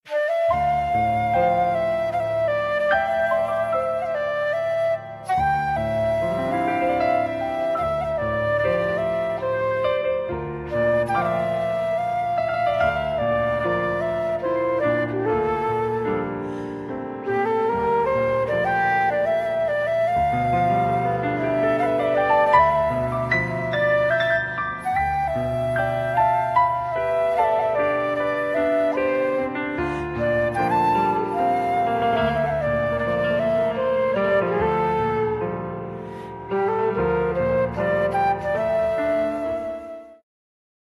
instrumenty perkusyjne
fortepian
flety drewniane, klarnet, szałamaje
skrzypce